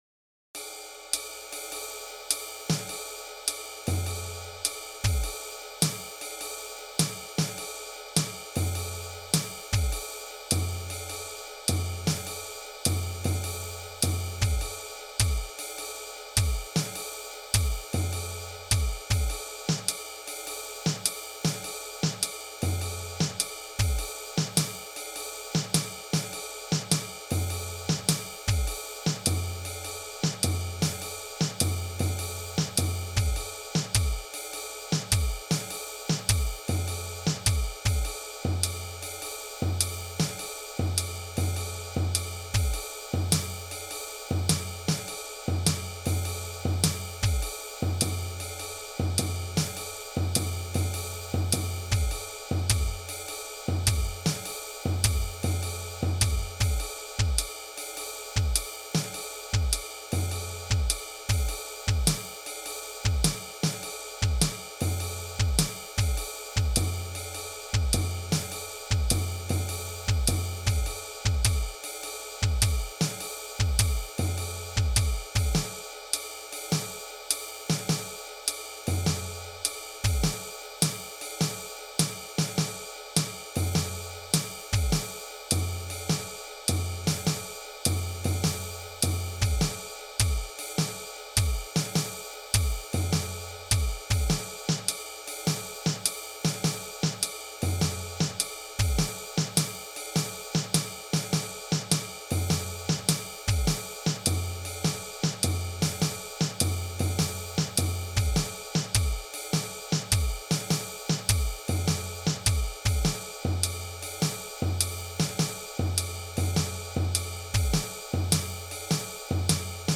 This time, let's have a basic swing pattern, and the left hand and the right foot will do the counting.
0 - silence
1 - snare
2 - tom
3 - kick
So it'll start with just a swing pattern and end with many added kicks.